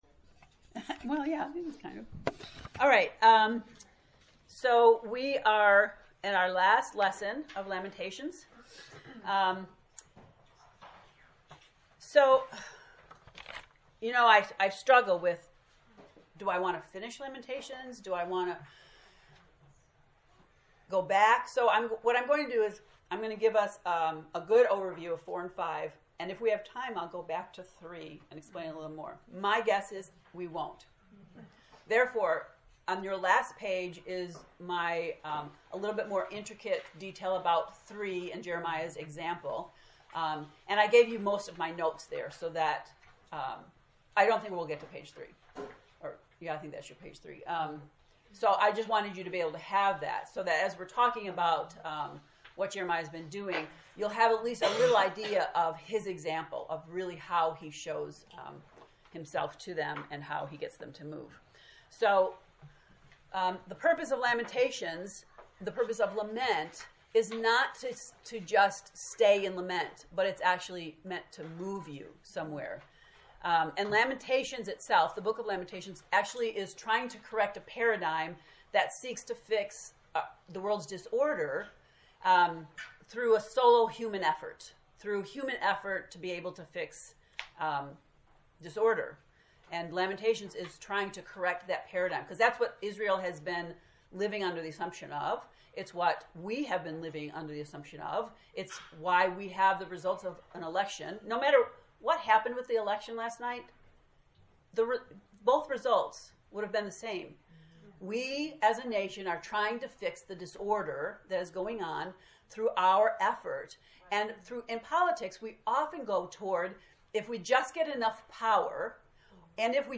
To listen to the lecture, “The Movement of Lament,” click below: